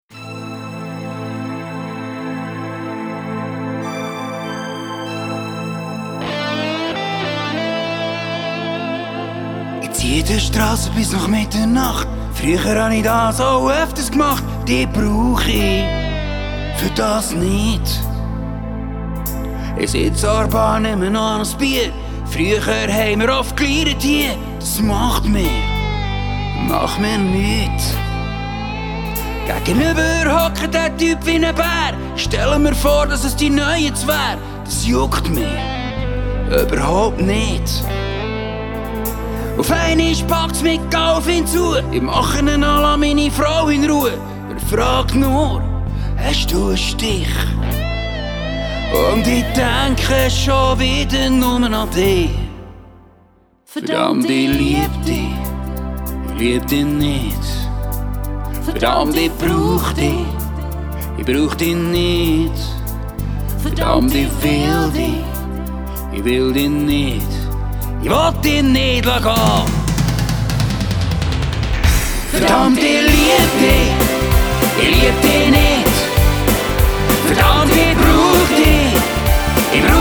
Genre: Pop-Mundart-Volk.